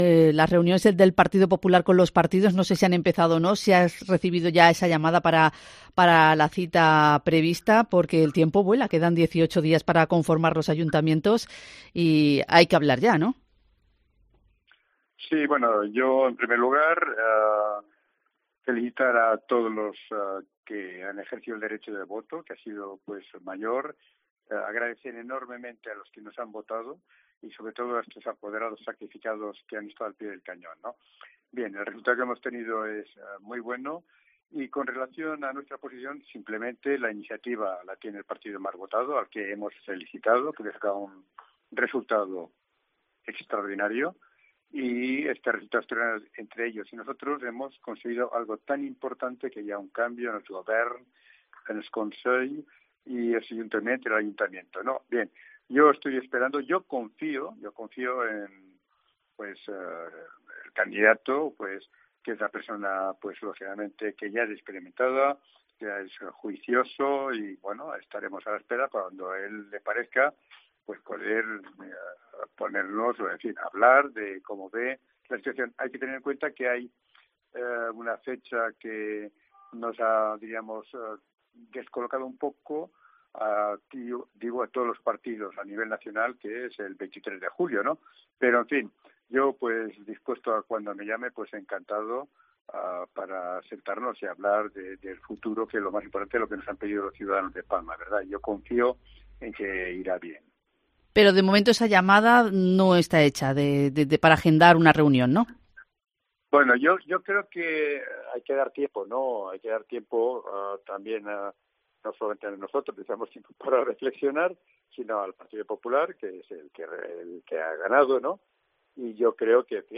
Entrevista a Fulgencio Coll, candidato de VOX Palma tras el 28M